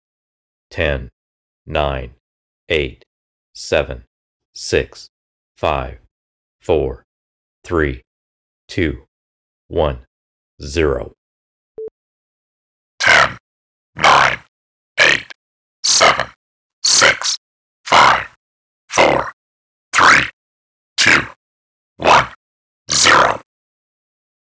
Dr Who Dalek synthesised voice
Frequency modulated, (not amplitude modulated), with a 37Hz square wave,